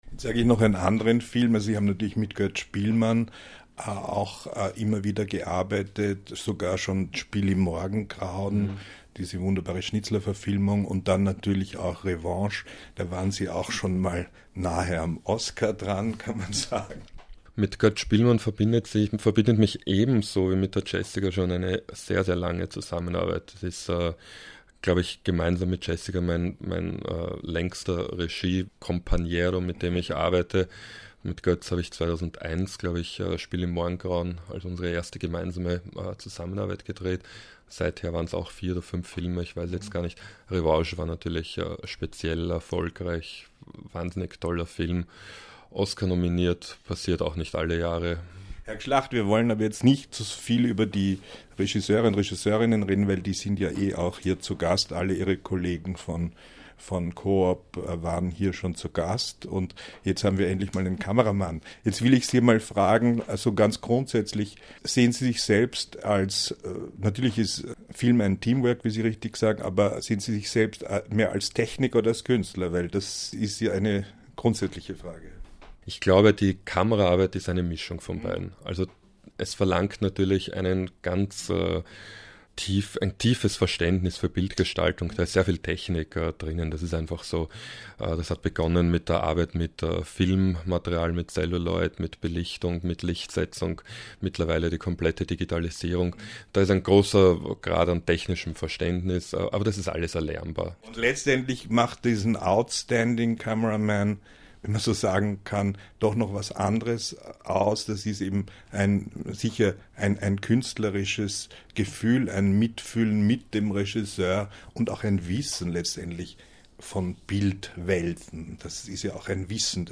INTERVIEWS radio (german)